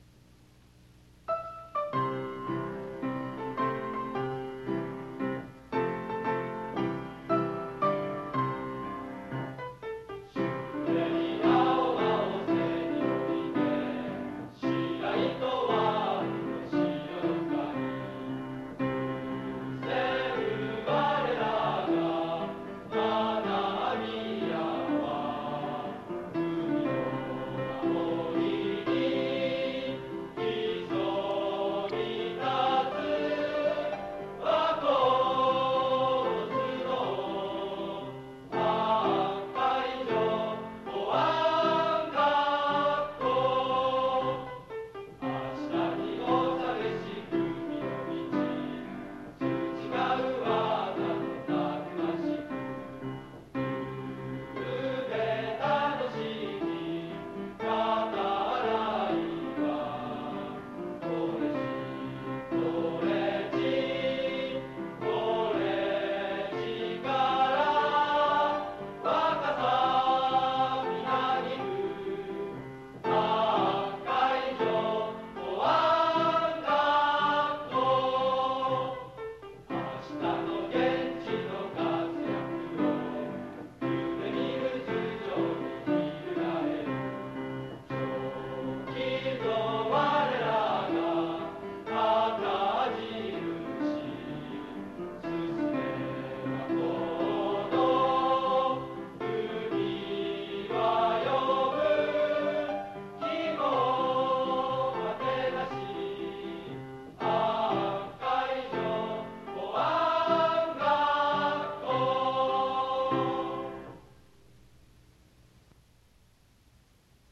■ 校歌（合唱）